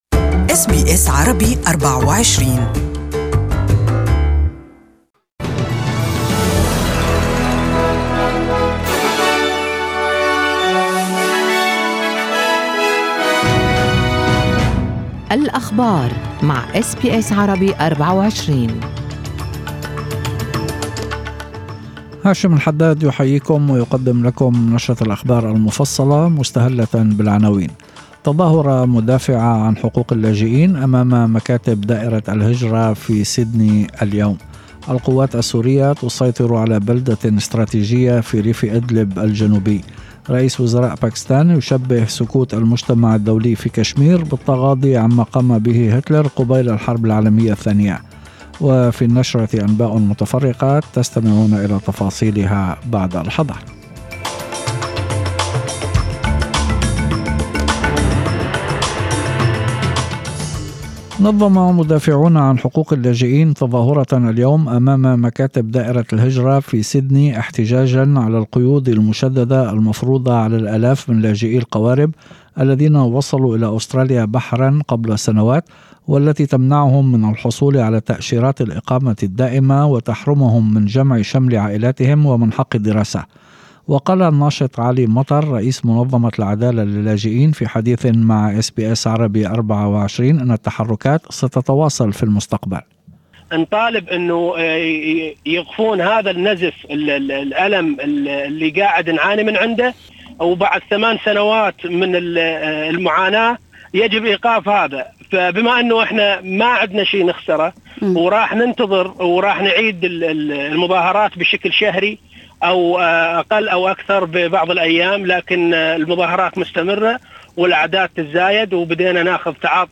يمكن الاستماع لنشرة الأخبار المفصلة باللغة العربية في التقرير الصوتي أعلاه.